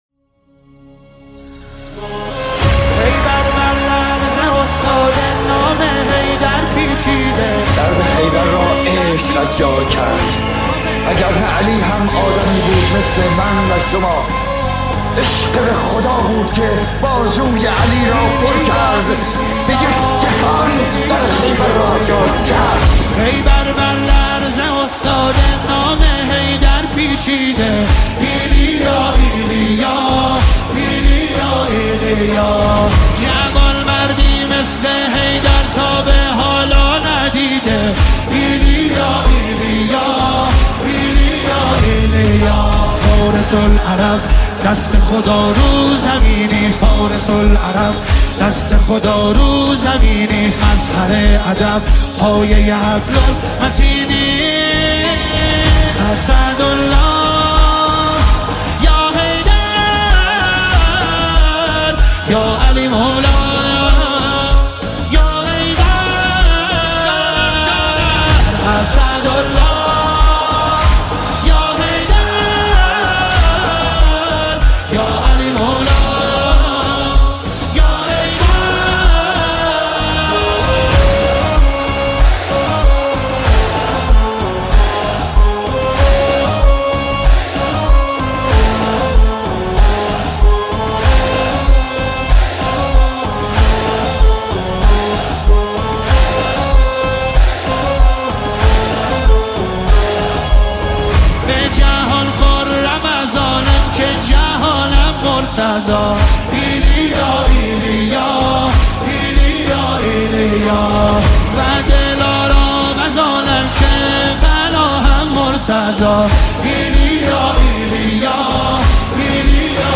دانلود نماهنگ دلنشین
نماهنگ مذهبی